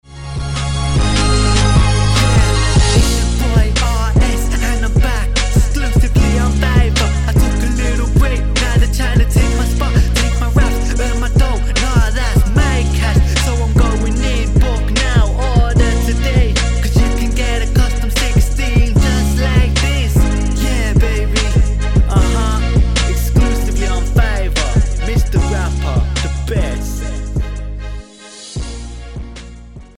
Cubase 10th mix (i deleted 60 takes and was harsh on my self) your opinions